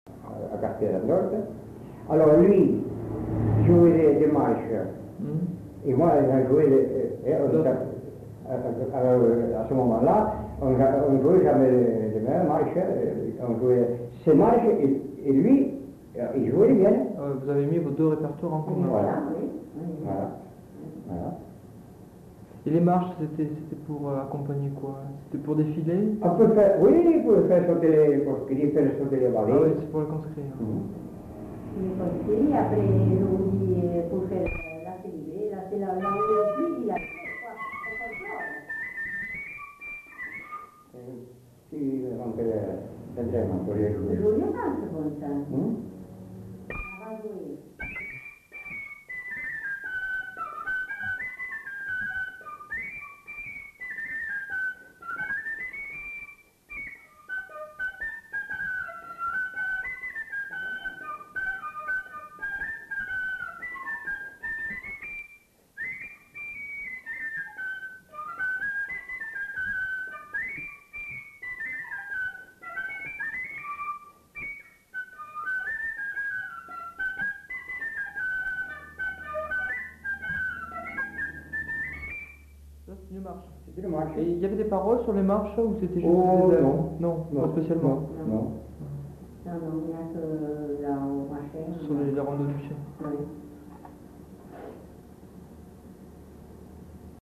Aire culturelle : Bazadais
Lieu : Bazas
Genre : morceau instrumental
Instrument de musique : fifre